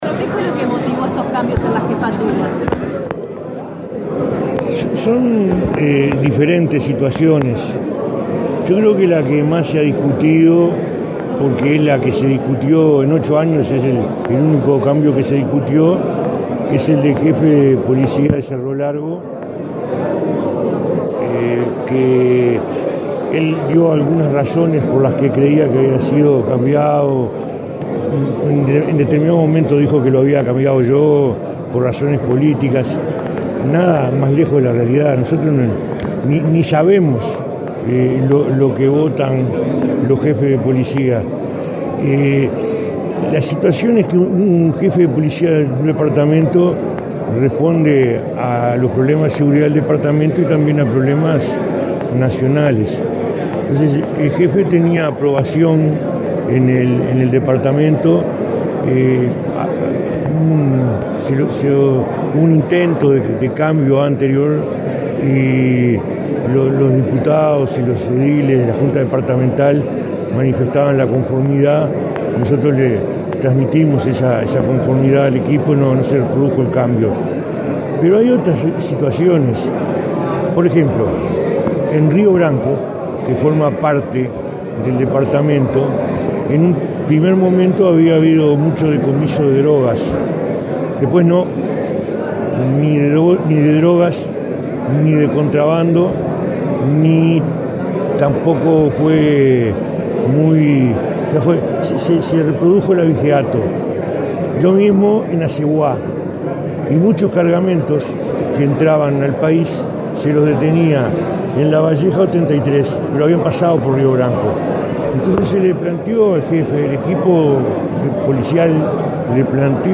El ministro del Interior, Eduardo Bonomi, habló en conferencia de prensa sobre el cambio de mando de Jefe de Cerro Largo.